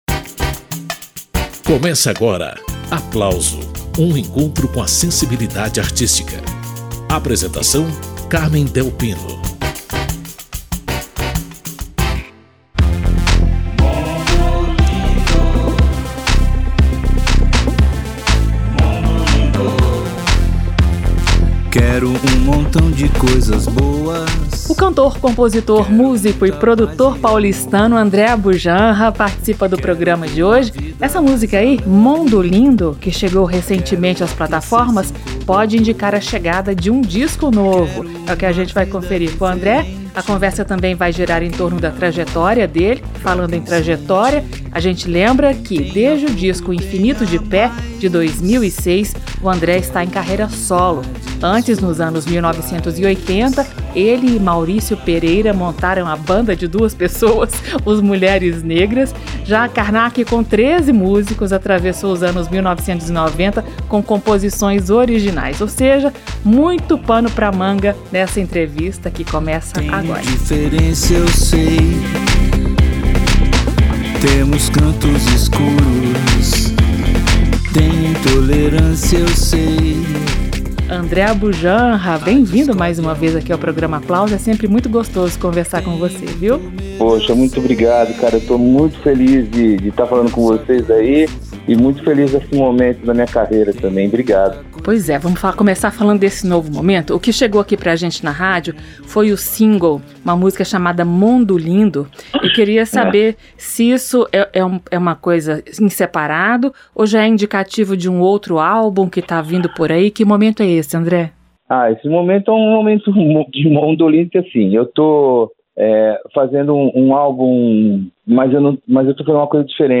Nesta edição do programa Aplauso, além dessas novidades, André Abujamra fala sobre os ensinamentos deixados pelo pai dele, o ator e diretor Antônio Abujamra; filosofa sobre religiosidade e imaginação; aponta utilidades criativas para Inteligência Artificial, entre outros assuntos, em bate-papo divertido.